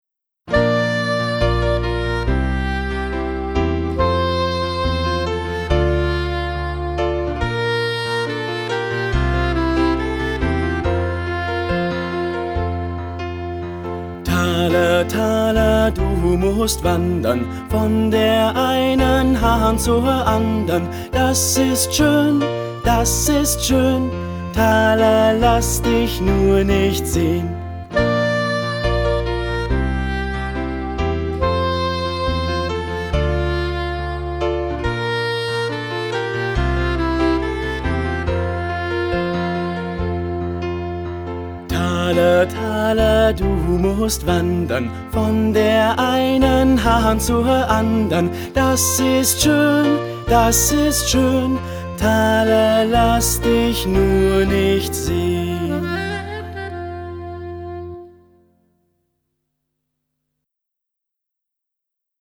Es gibt Spiellieder, Gutenacht-Lieder, Lieder zu den Jahreszeiten, Weihnachtslieder und viele mehr - z. B. Häschen in der Grube, Alle meine Entchen, Der Mond ist aufgegangen, Laterne, Laterne, O Tannenbaum oder Scheeflöckchen, Weißröckchen.
Bekannte und beliebte Kinderlieder für die Kleinen, von kleinen und großen Solisten gesungen.